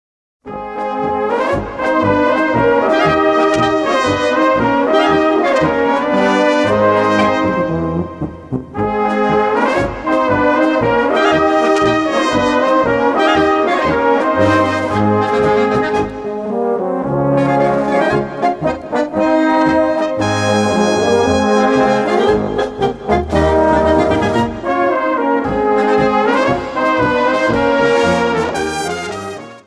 Gattung: Polka
Besetzung: Blasorchester
Eine wunderschöne böhmische Polka